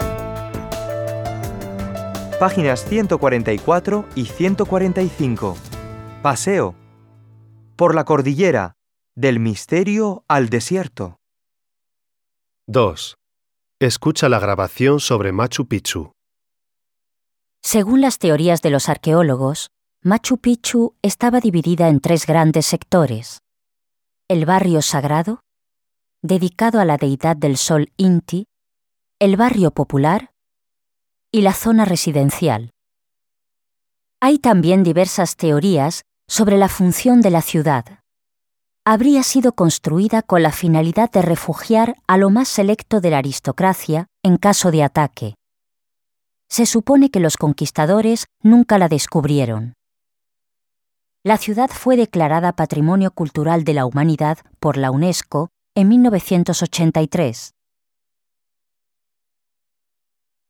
SECUENCIA 16: Comprensión oral: el Machu Picchu
Pista del libro